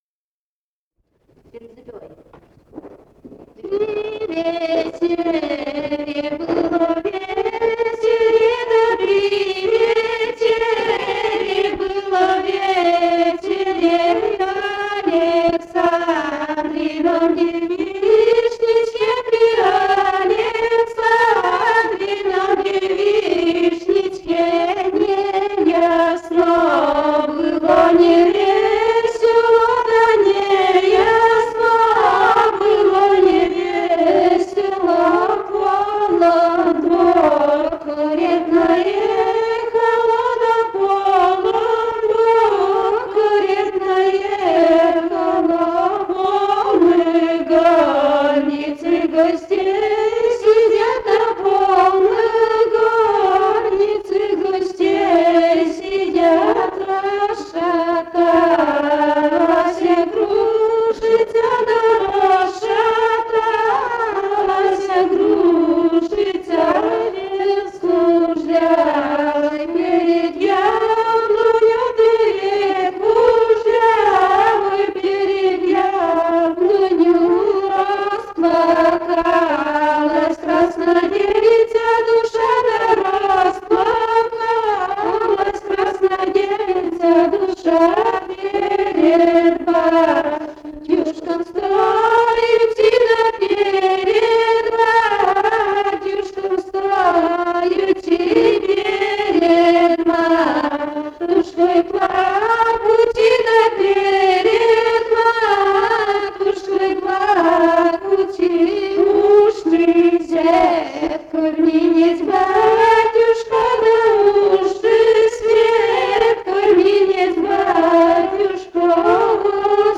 Живые голоса прошлого 045. «При вечере, было вечере» (свадебная на девишнике).